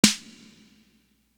Trinitron Snare.wav